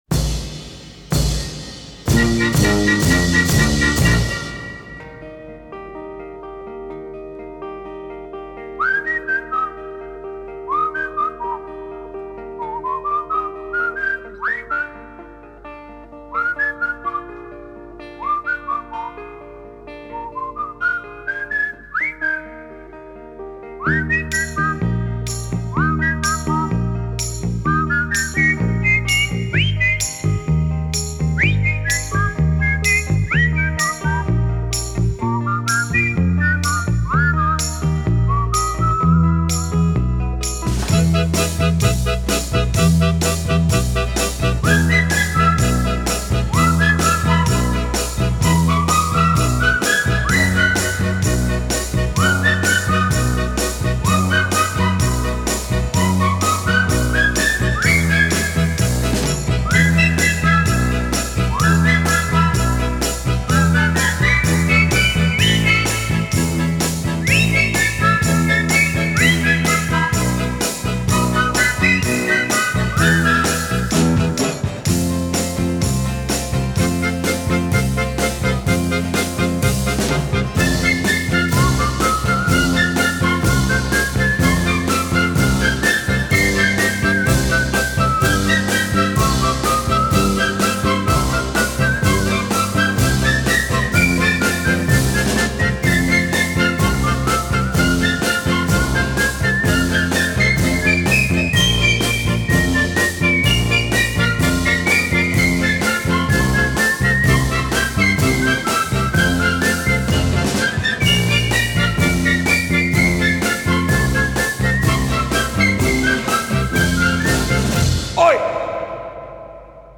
gwizdanie................_.mp3